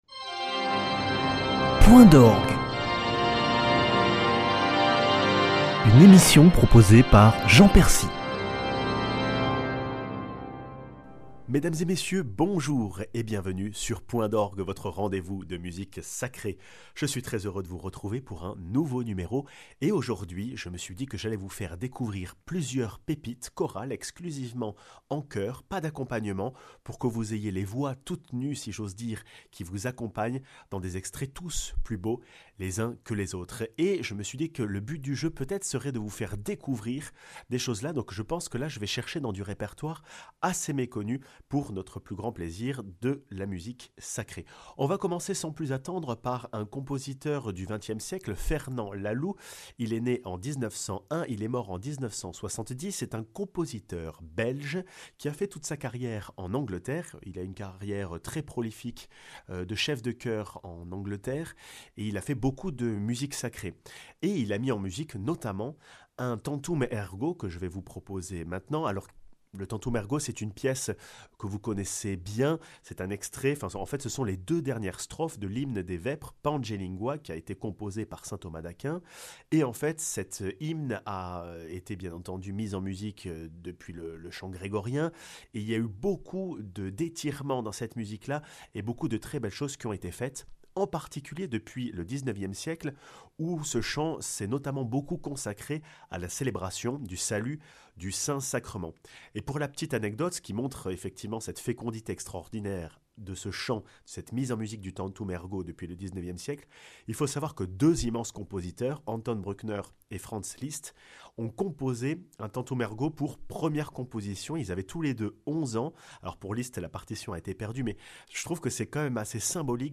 Point d'orgue vous amène à la découverte d'oeuvres chorales du XXème siècle, hautes en couleurs méditatives (Tantum Ergo de Laloux, Lux Eterna d'Elgard, Ave Maris stella de Sabate et Vinea mea electa de Poulenc)